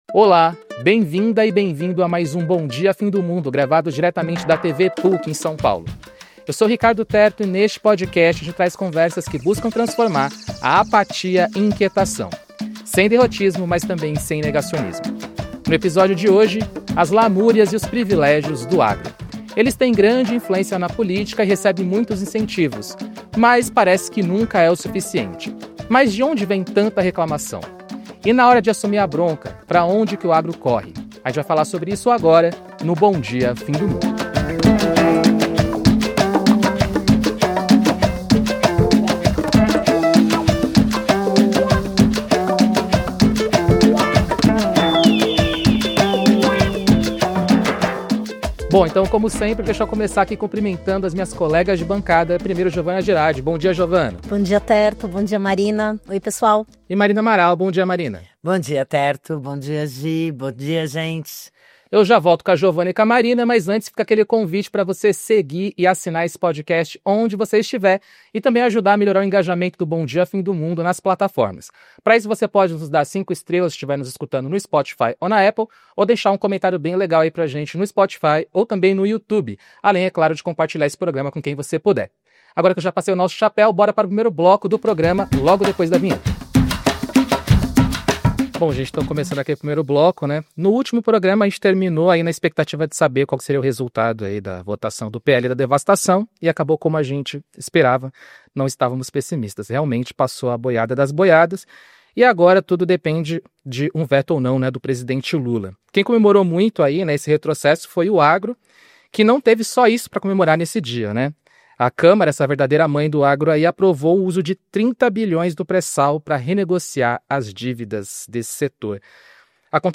24 de abril de 2026 · Mesa discute de que forma o jornalismo pode contribuir para a mobilização social